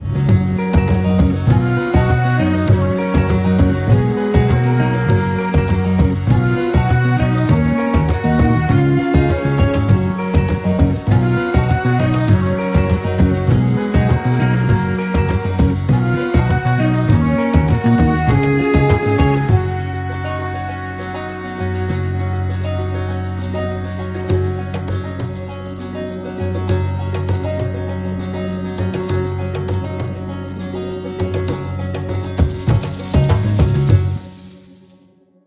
Нарезка на смс или будильник